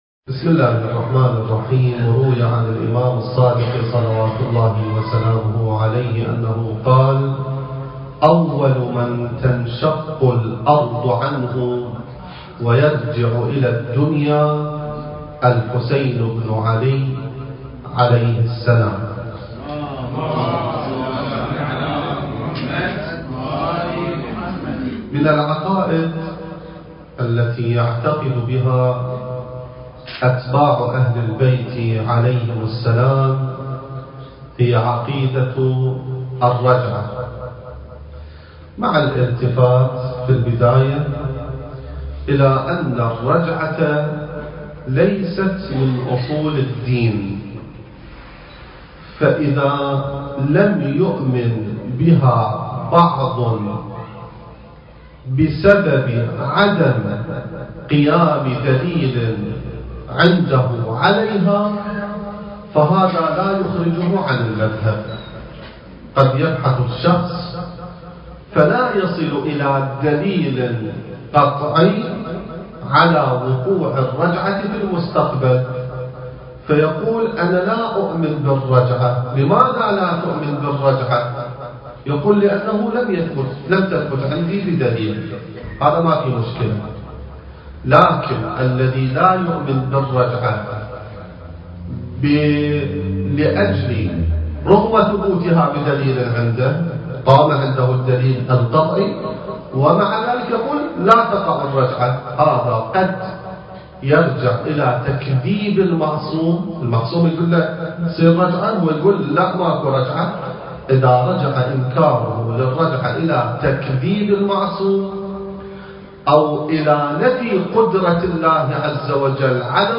المكان: مؤسسة الإمام السجاد (عليه السلام) / استراليا التاريخ: 2019